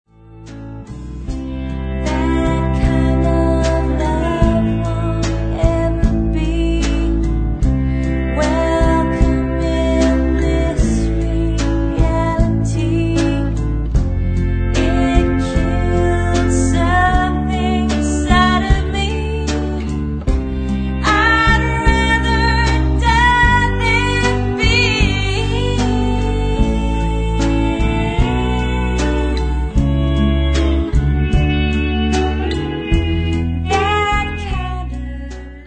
Haunting mellow songs
dual vocals
bittersweet tunes